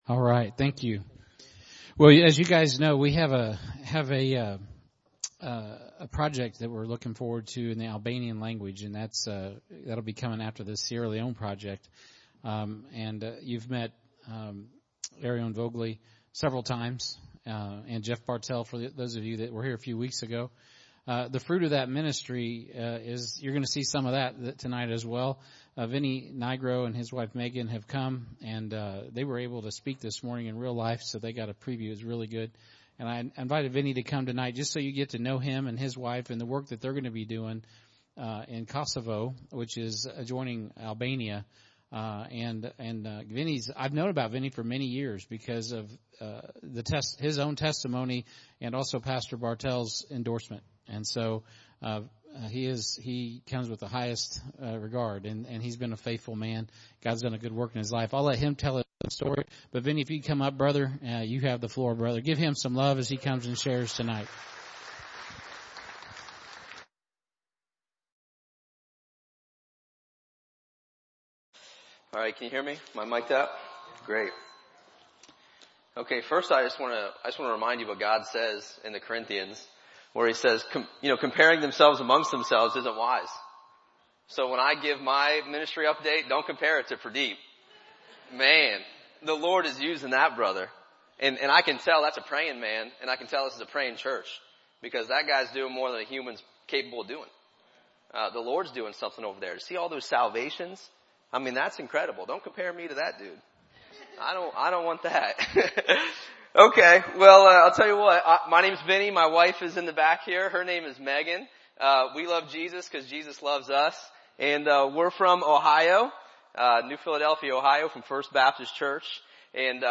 Missionary Testimony